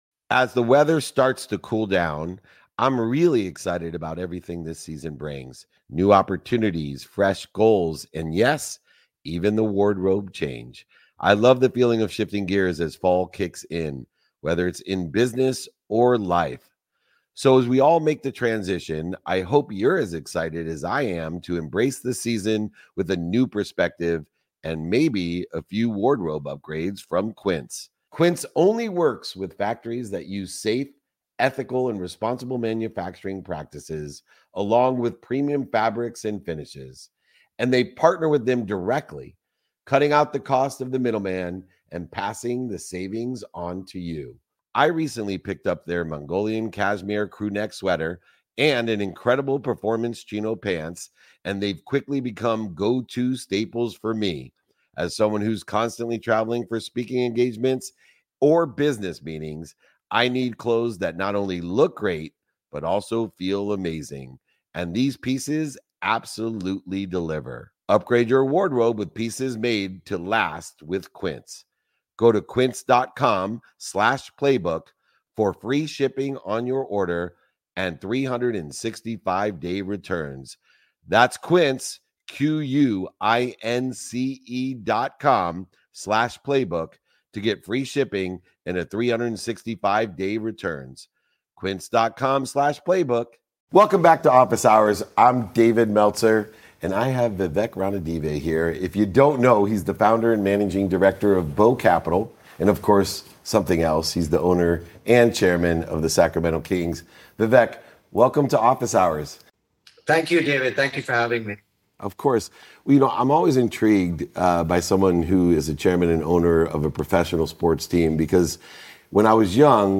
In today’s episode, I speak with Vivek Ranadivé, owner and chairman of the Sacramento Kings, entrepreneur, and visionary behind Bow Capital. Vivek shares his incredible journey from arriving in America with $50 in his pocket to becoming a leader in technology and professional sports. He reflects on how his fascination with innovation and teamwork helped him turn dreams into reality, from studying at MIT to reshaping basketball culture.